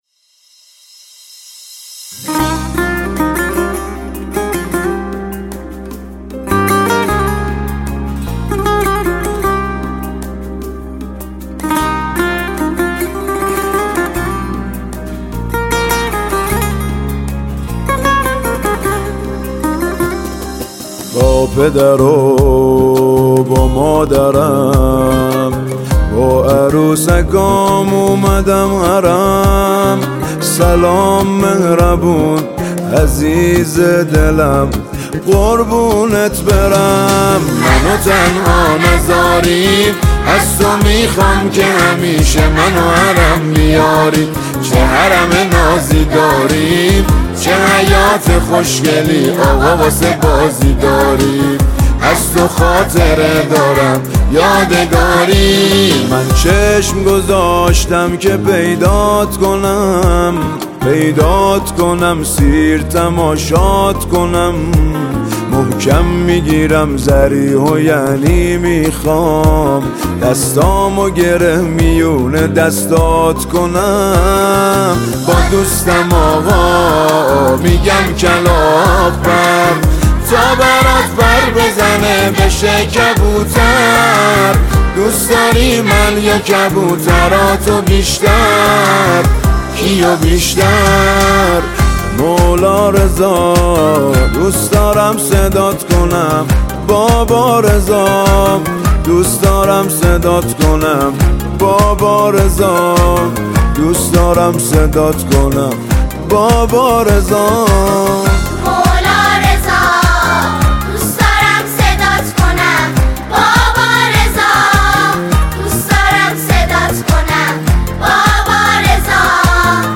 نماهنگ مذهبی